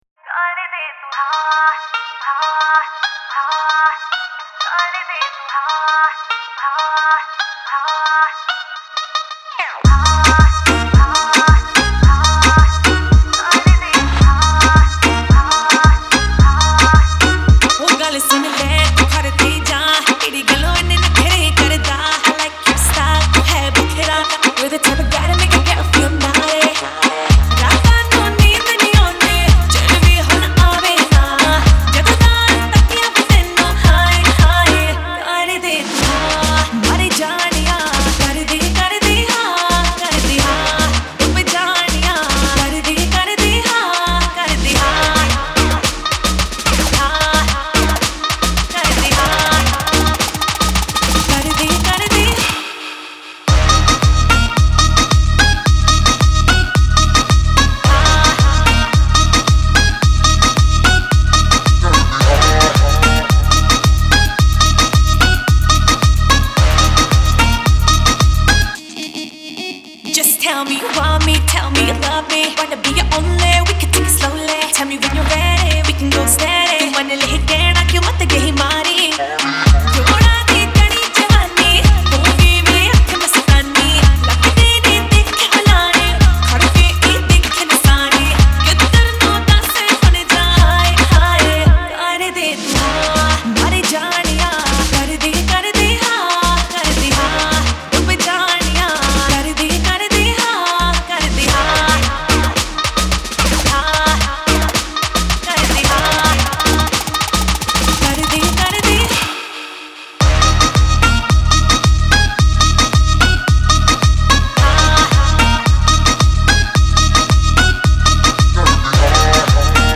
Punjabi DJ Remix